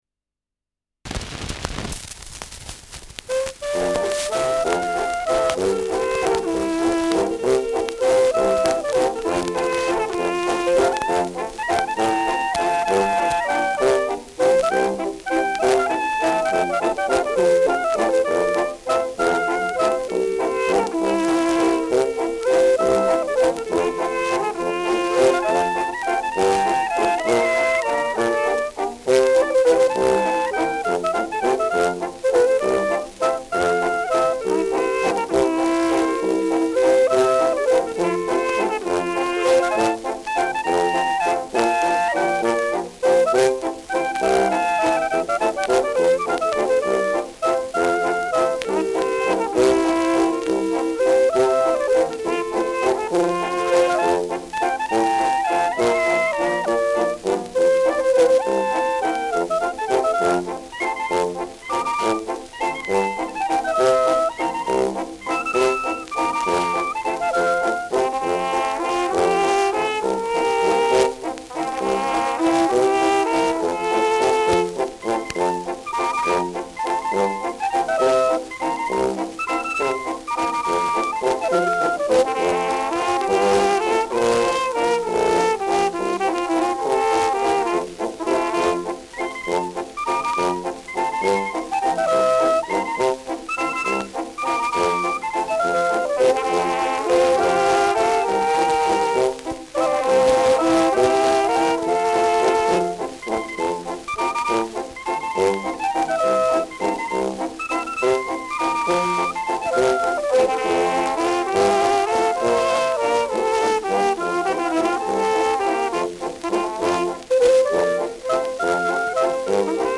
Schellackplatte
Stärkeres Grundrauschen : Zischen zu Beginn : Durchgehend leichtes bis stärkeres Knacken : Verzerrt an lauten Stellen
Kapelle Die Alten, Alfeld (Interpretation)
Mit Juchzern.